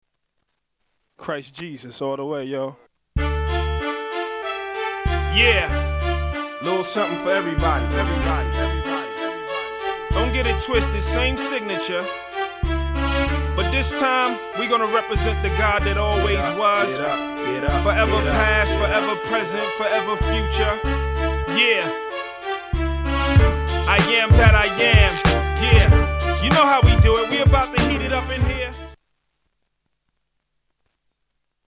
STYLE: Hip-Hop
multi-mc approach
head-nodding east coast style